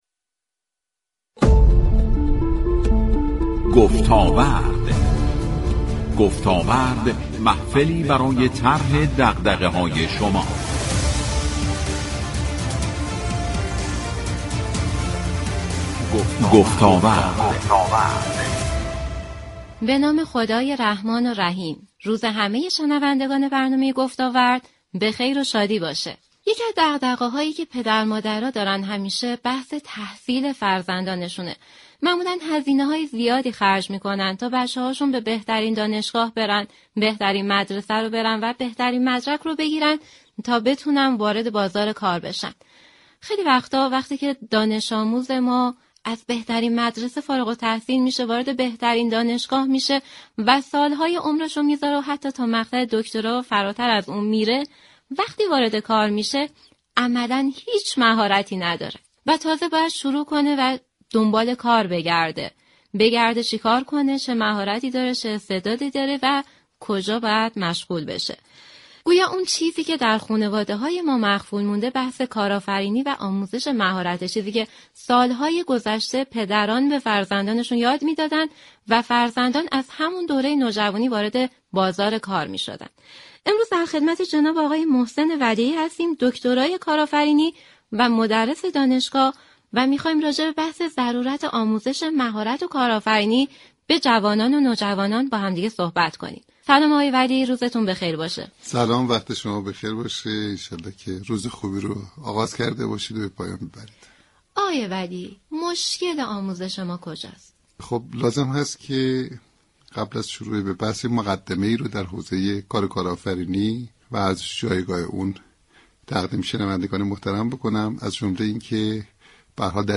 یك كارشناس حوزه كارآفریی گفت: كارآفرینی؛ كاری بسیار پر زحمت و نیازمند برنامه‌ریزی دقیق است.